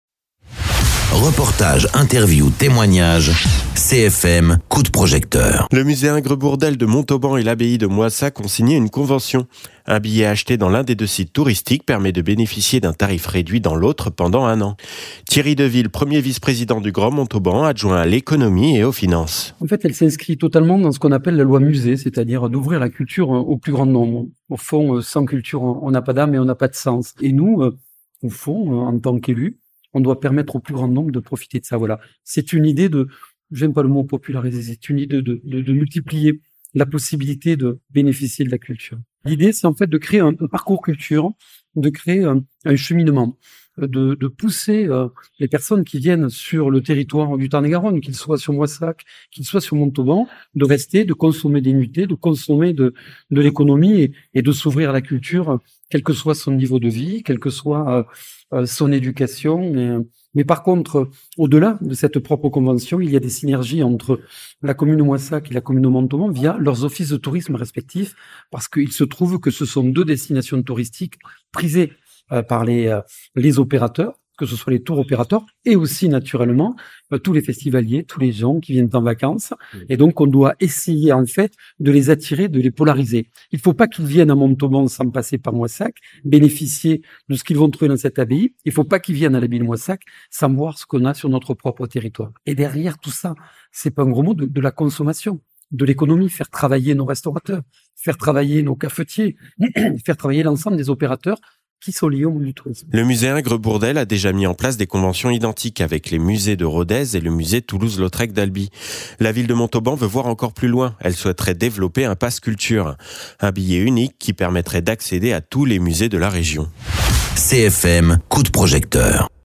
Interviews
Invité(s) : Thierry Deville, 1er vice-président du Grand Montauban, adjoint à l’économie et aux finances.